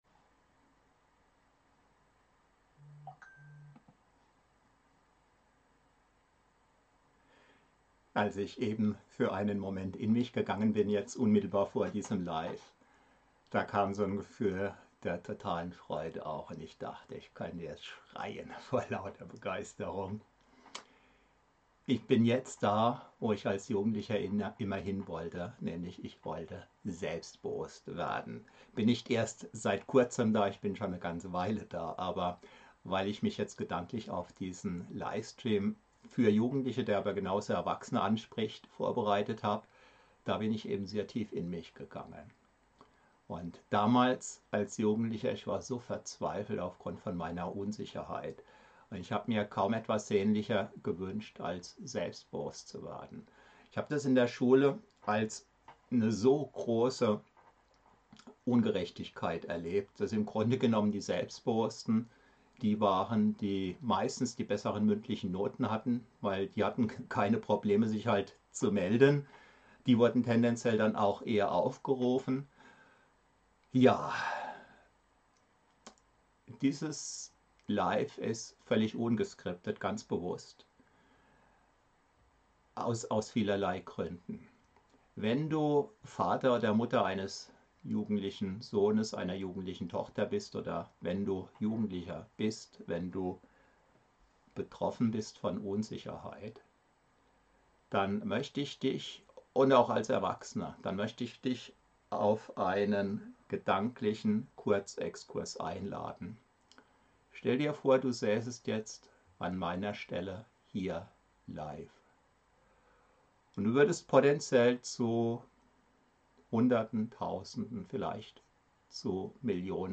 Ungescripteter Livestream